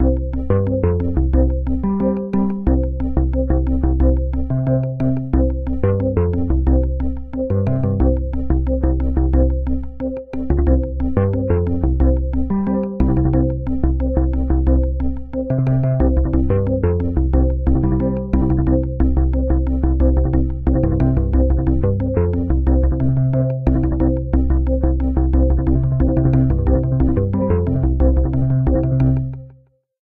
outgoing-call.oga